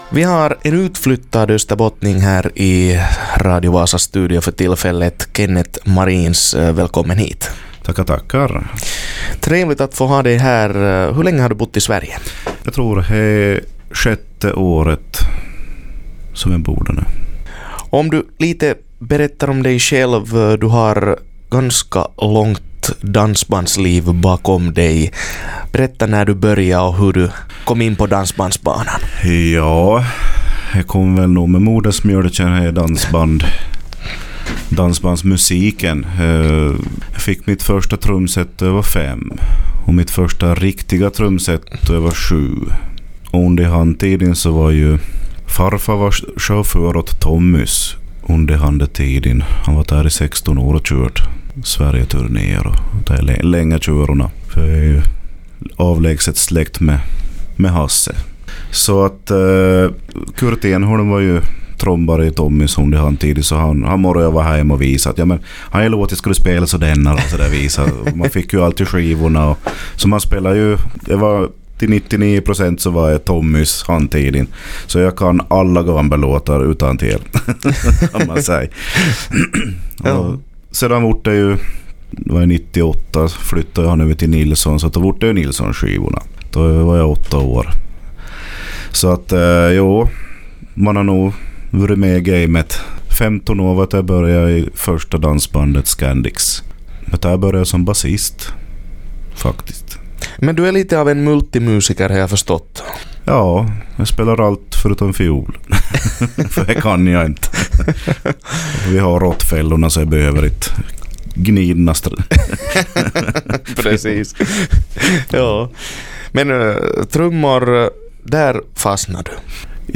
Dansband